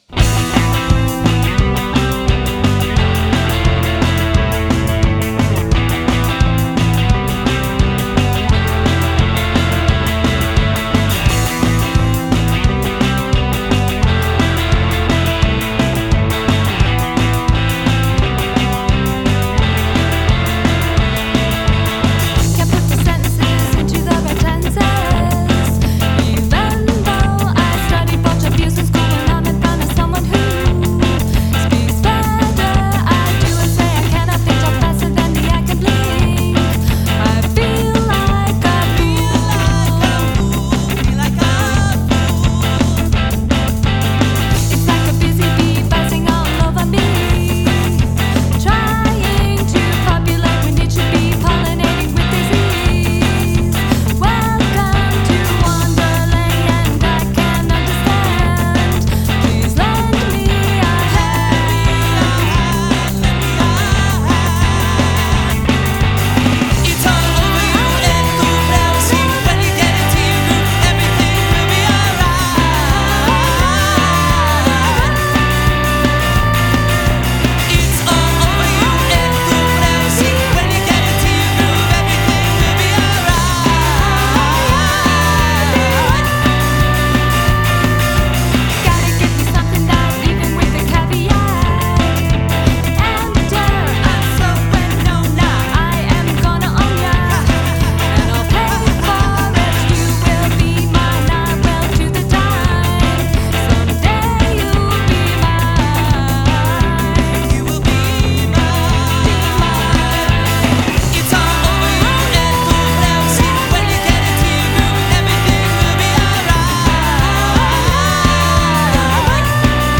driving rhythms